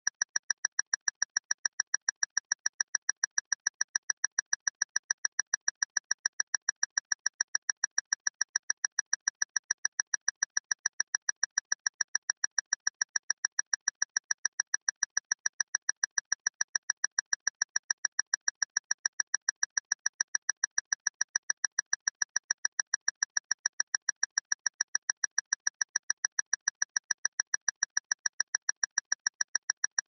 Звуки таймера, отсчёта
Быстрое тиканье — 30 секунд